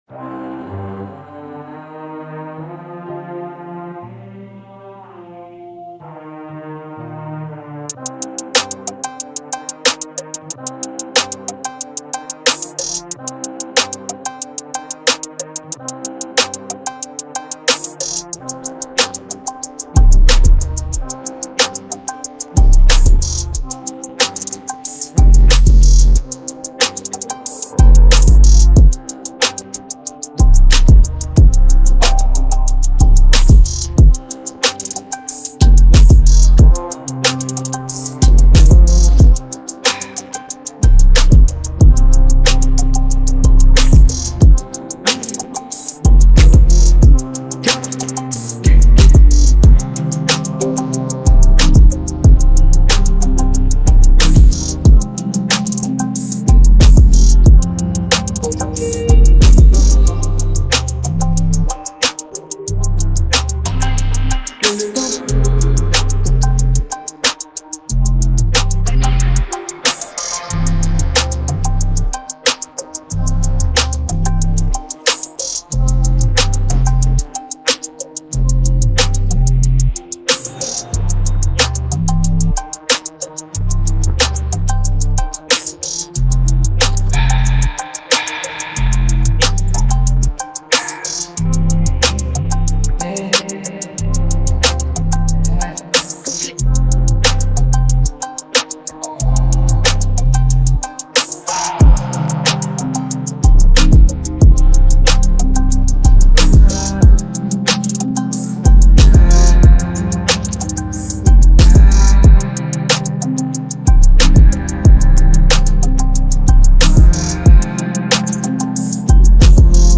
بی‌کلام
Hip-Hop